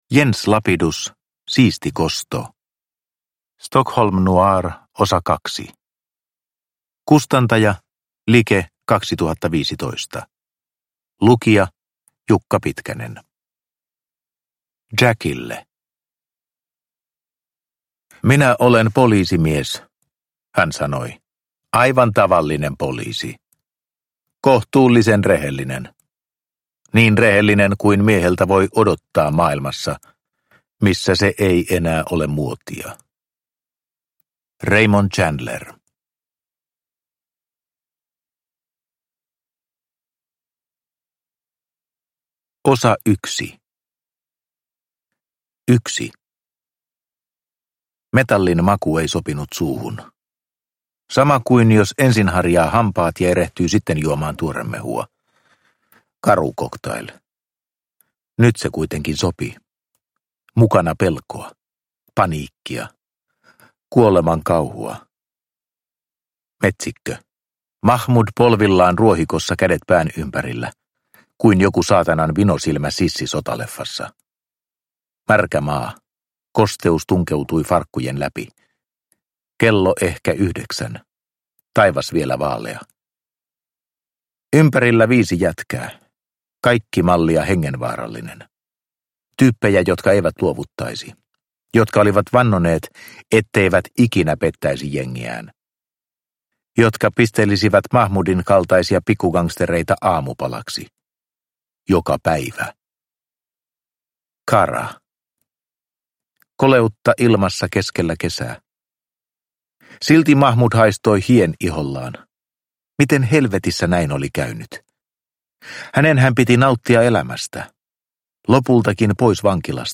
Siisti kosto – Ljudbok – Laddas ner